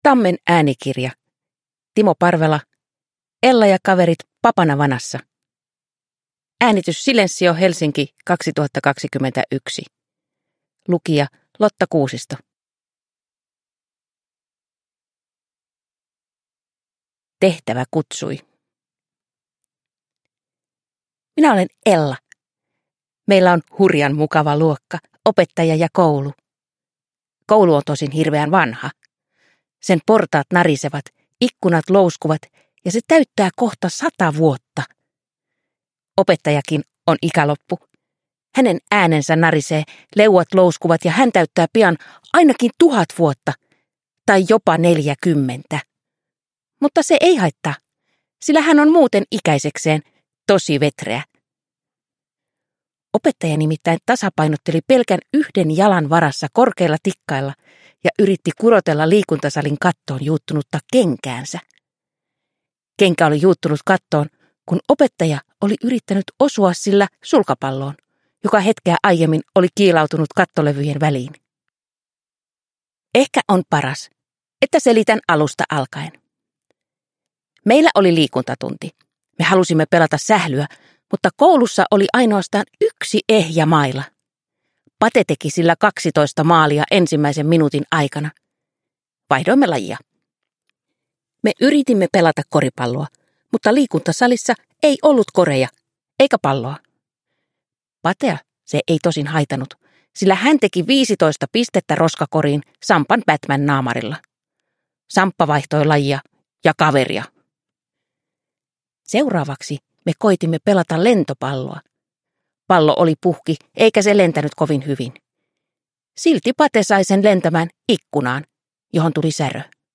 Ella ja kaverit papanavanassa – Ljudbok – Laddas ner